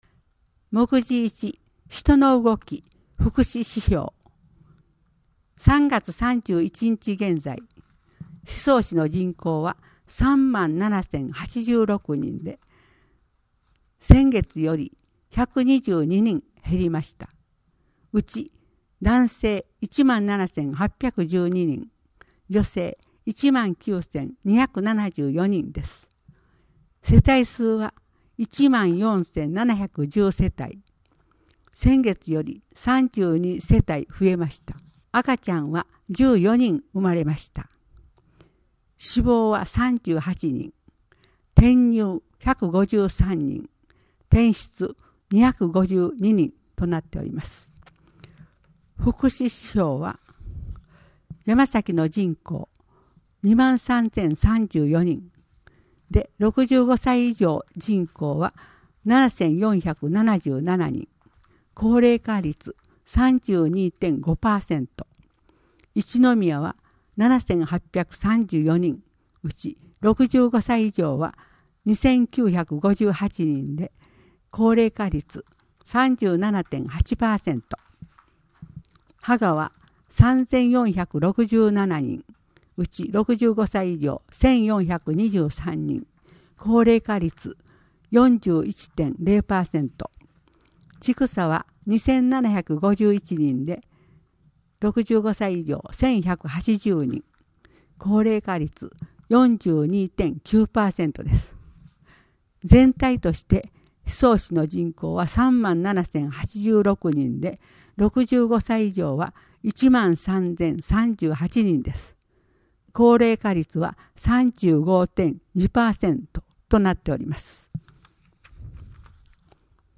このページでは、広報しそうの記事が録音された「声の広報」が楽しめます。